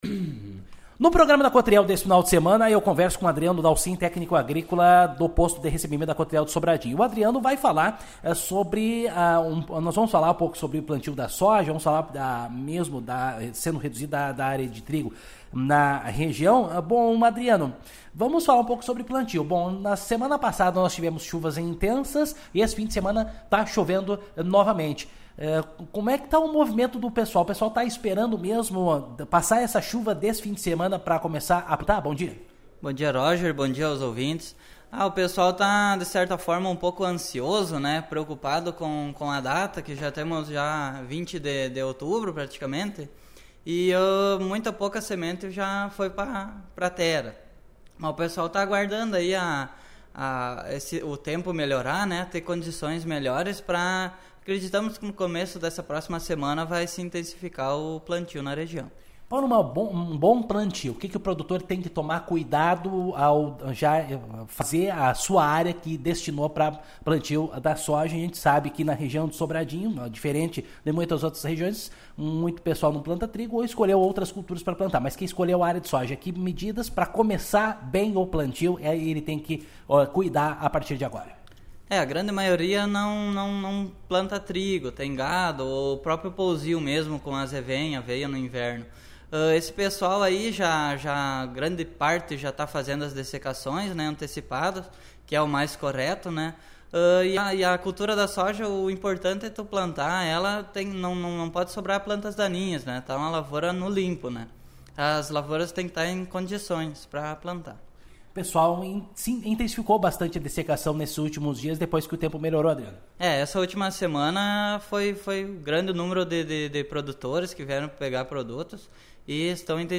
aqui a entrevista na íntegra.